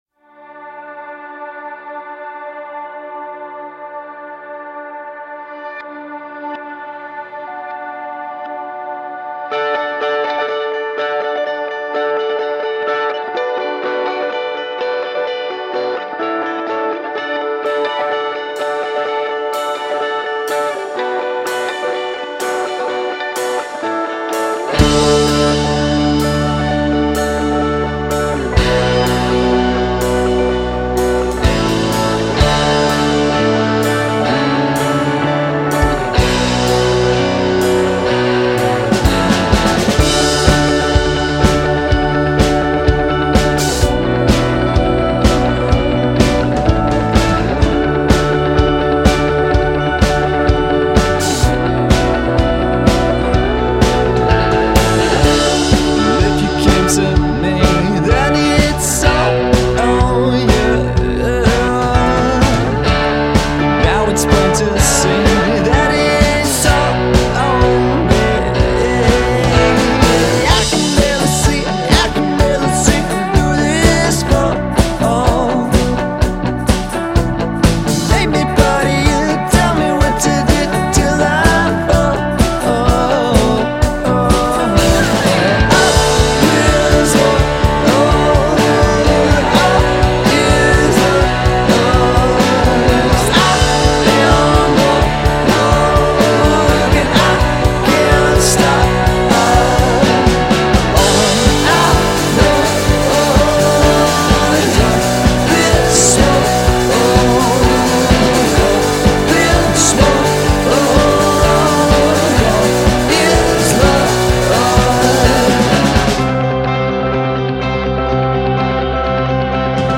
dynamic rock band